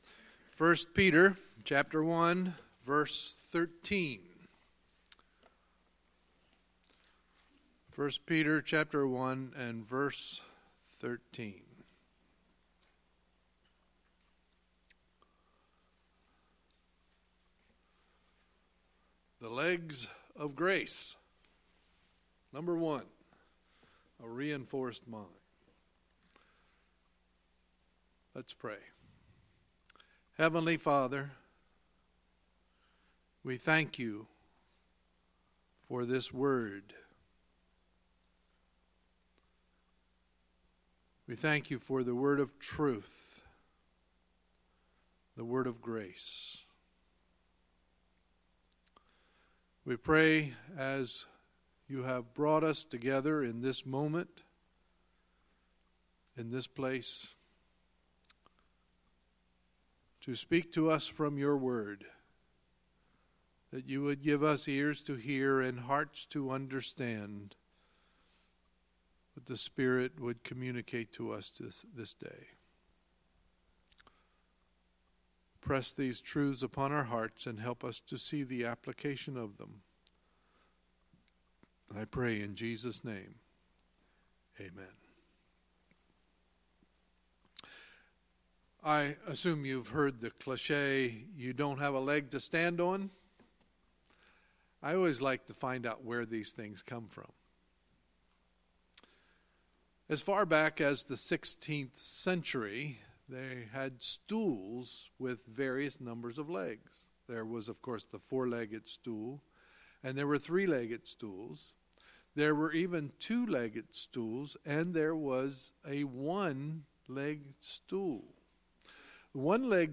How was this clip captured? Sunday, February 11, 2018 – Sunday Morning Service